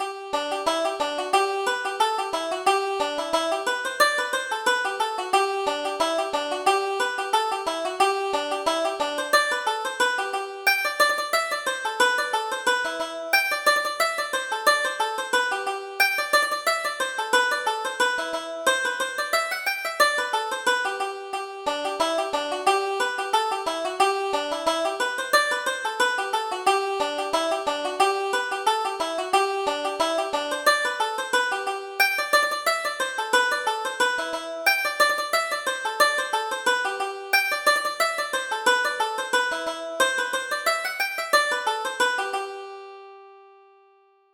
Reel: The Bloom of Youth